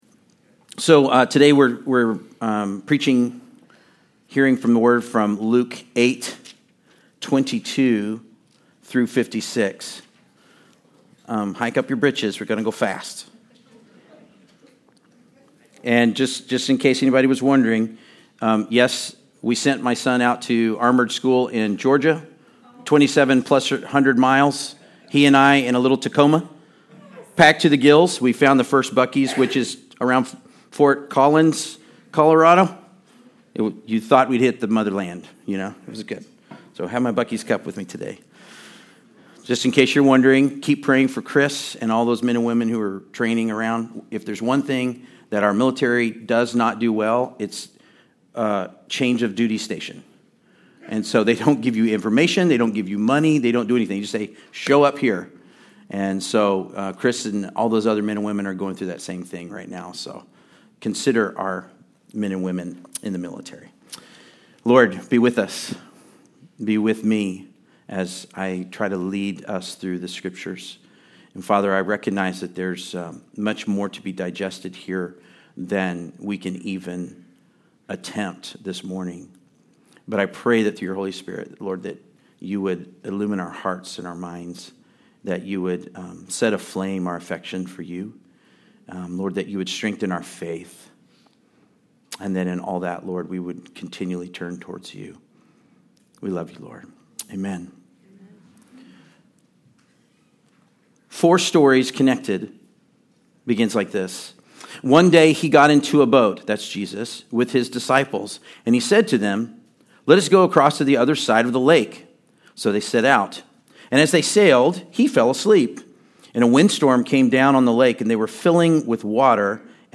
Passage: Luke 8:22-56 Service Type: Sunday Service